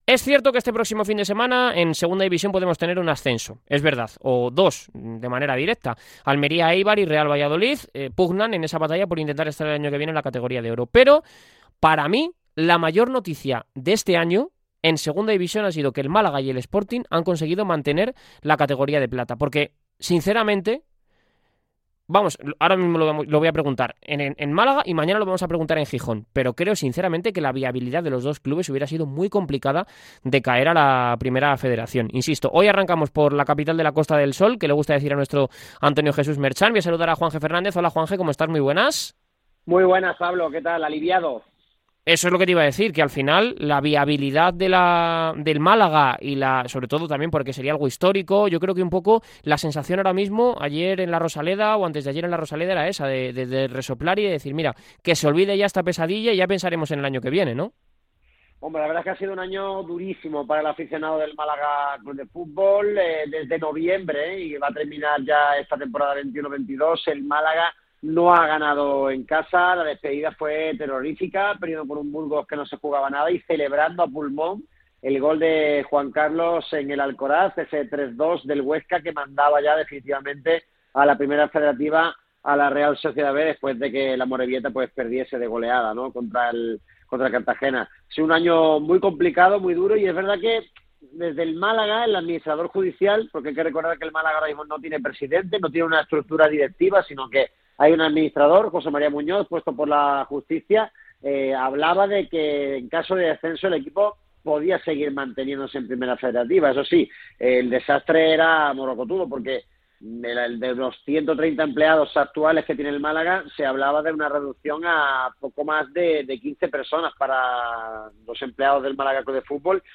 Compartir esta publicación Compartir Facebook Twitter-x Instagramm Correo electrónico Copy URL to clipboard Audio de la entrevista en Radio MARCA Gonzalo de los Santos es toda una institución en la historia del Málaga CF .